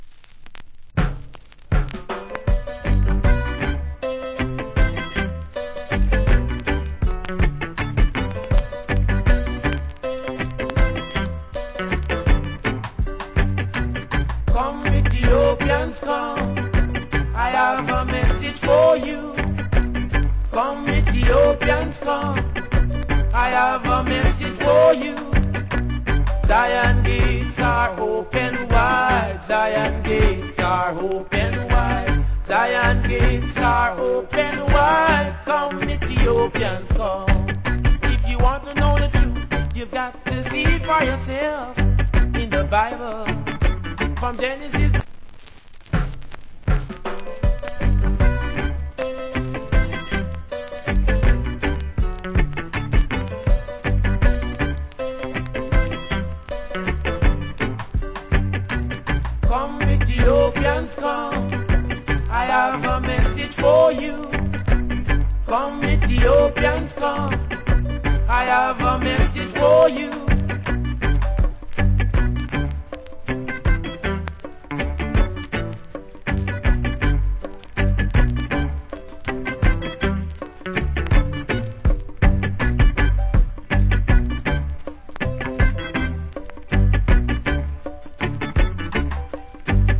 Wicked Early Rasta Reggae Song.